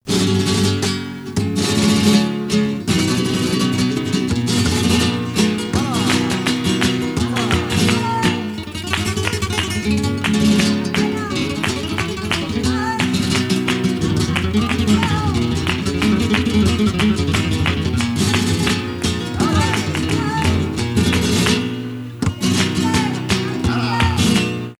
Tangos flamencos. Toque de guitarras.
tango
flamenco
guitarra
Sonidos: Música